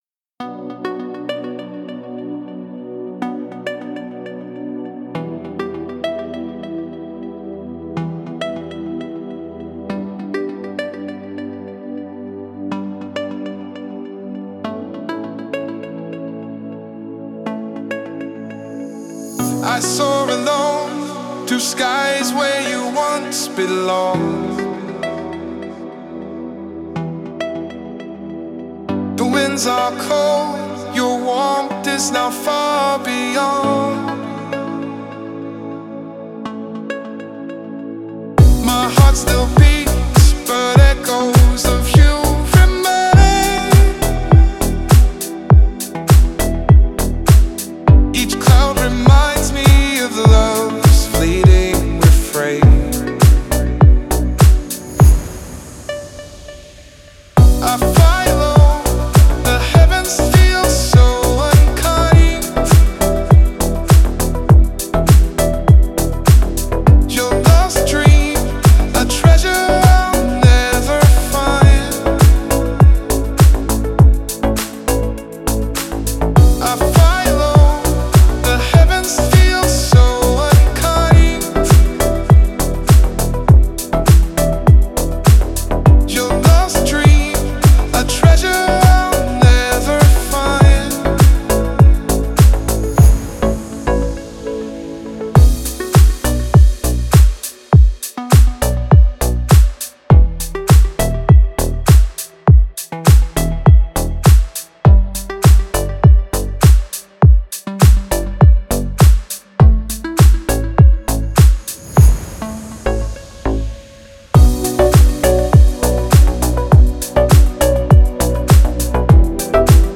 Deep House музыка
красивый Deep House